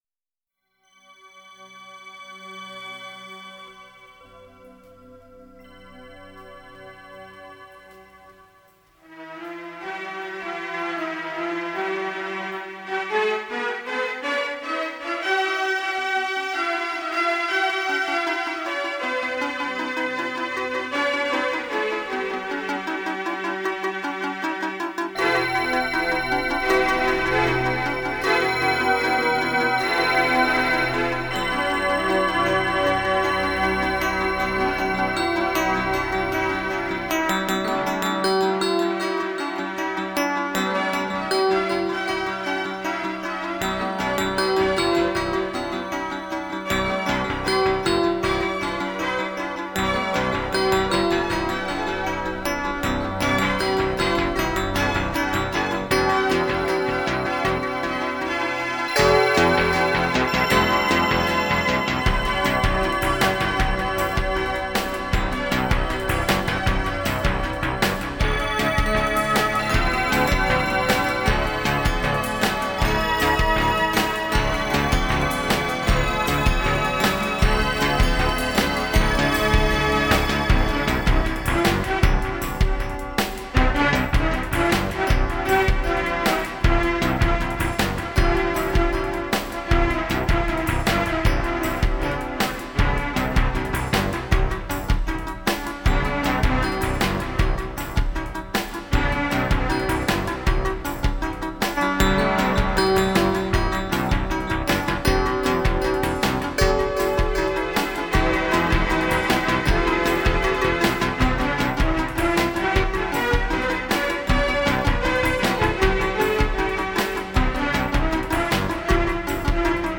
ALL INSTRUMENTAL SOLO PROJECTS